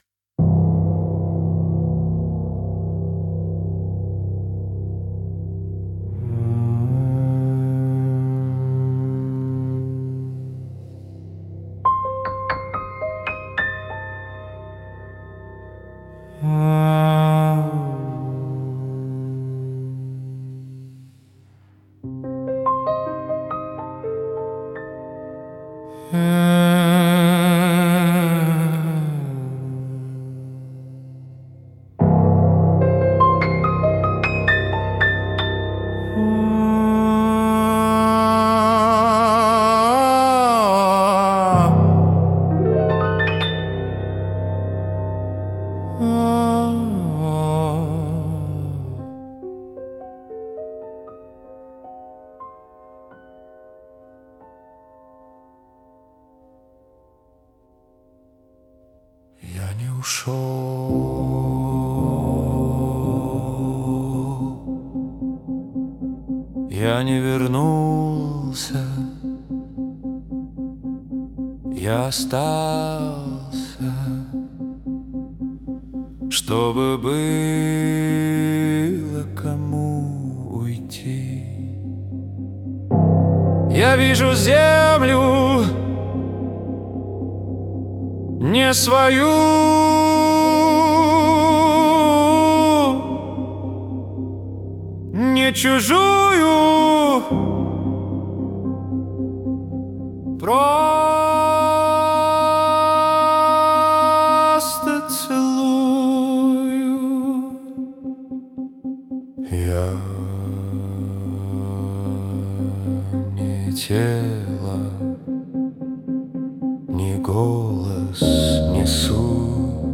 ambient experimental rock / theatrical minimalism